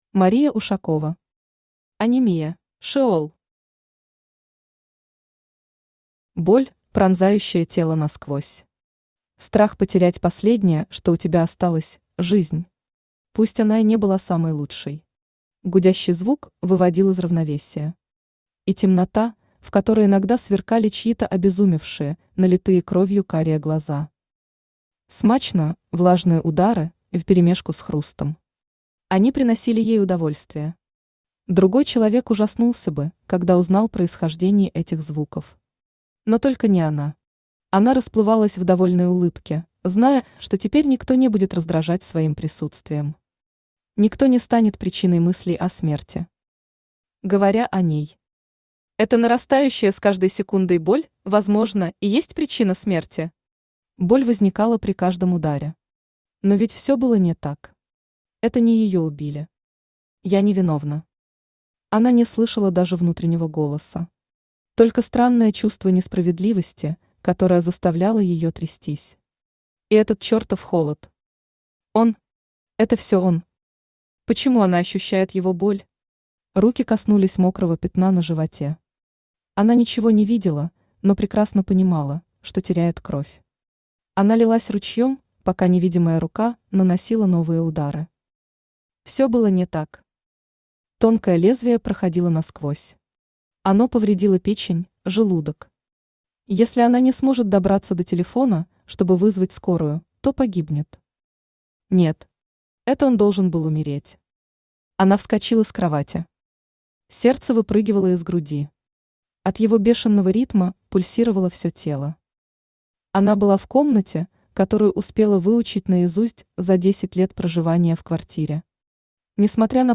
Аудиокнига Анемия | Библиотека аудиокниг
Aудиокнига Анемия Автор Мария Александровна Ушакова Читает аудиокнигу Авточтец ЛитРес.